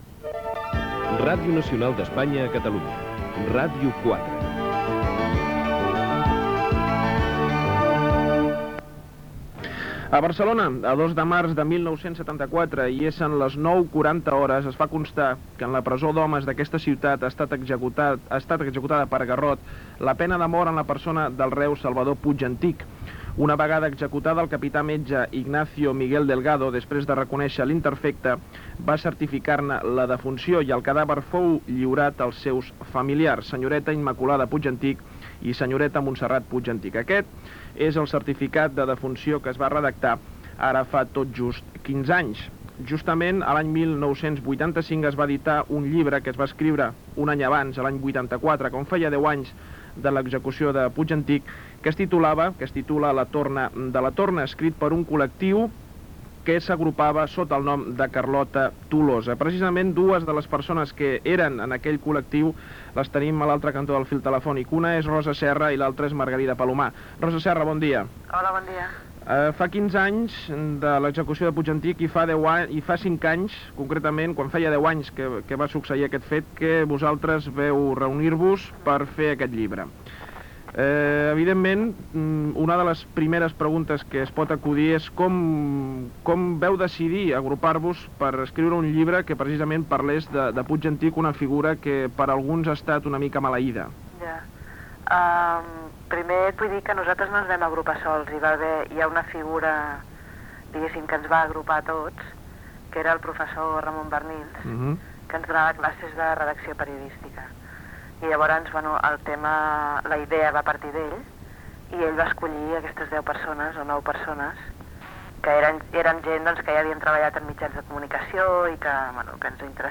Indicatiu de l'emissora
Entrevista telefònica